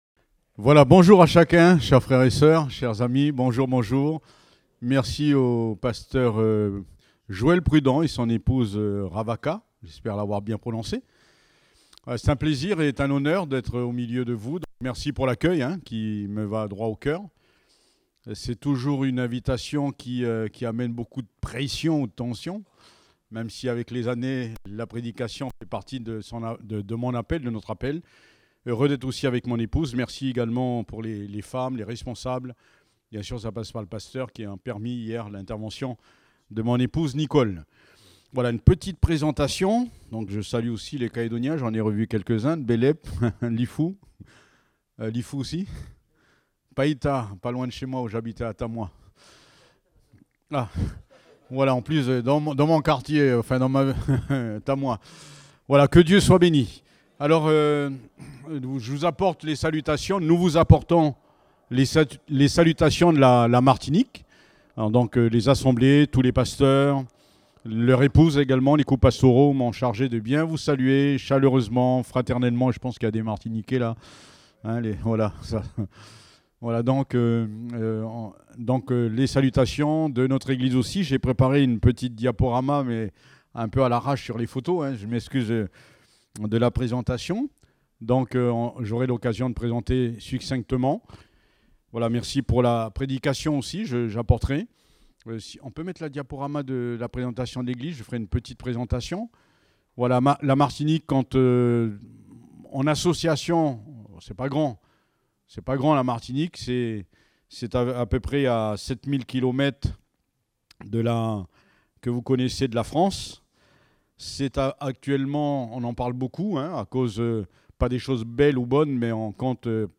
Date : 24 mars 2024 (Culte Dominical)